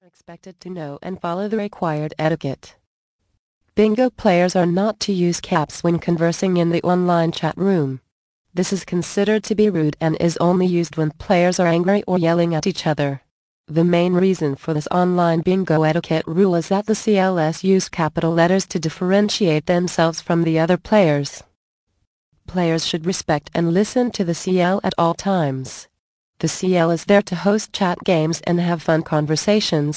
Bingo Winning Secrets. Audio Book. Vol. 5 of 7. 49 min.